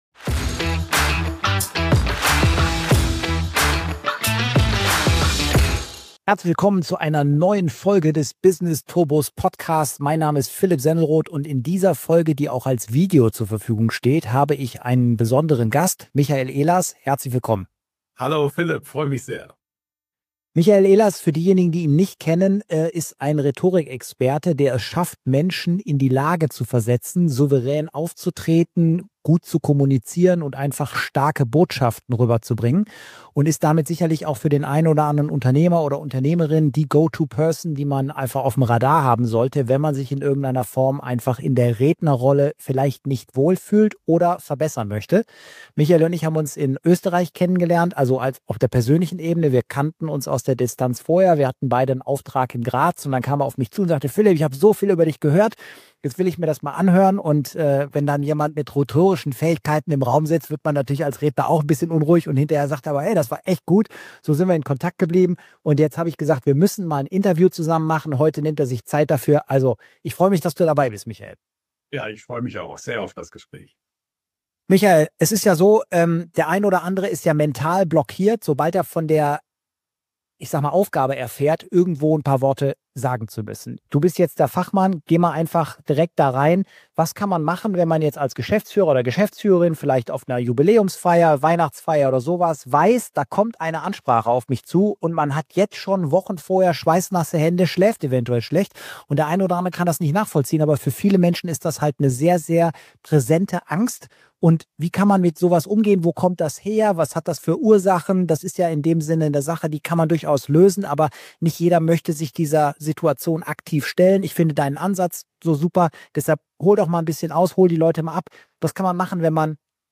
#060 - INTERVIEW